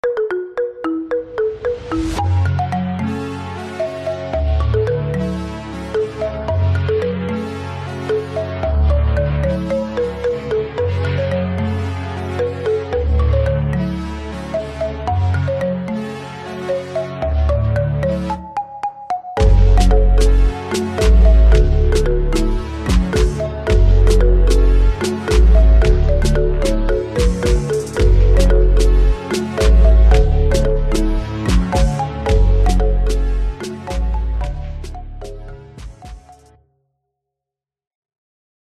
Marimba